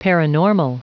Prononciation du mot paranormal en anglais (fichier audio)
Prononciation du mot : paranormal
paranormal.wav